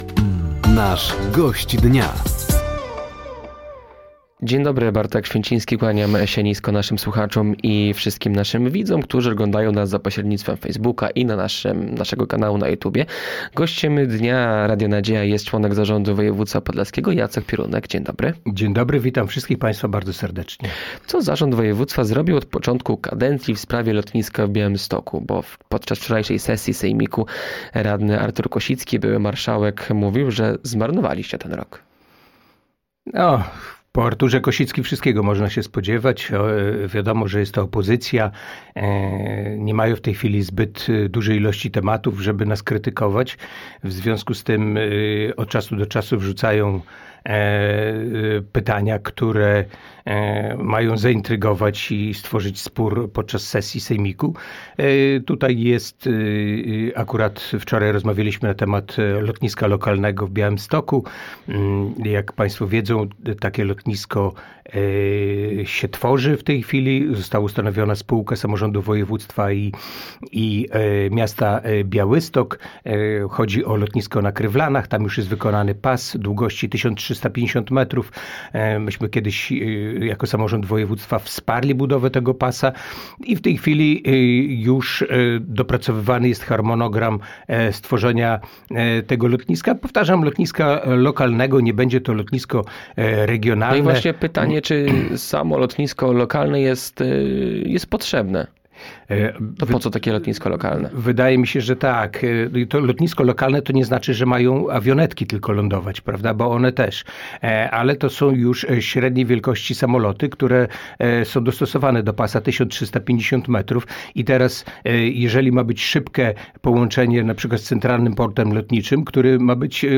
Gościem Dnia Radia Nadzieja był Jacek Piorunek, członek zarządu województwa podlaskiego. Tematem rozmowy było między innymi lotnisko w Białymstoku, migranci oraz przyszłość łomżyńskiego WORD-u.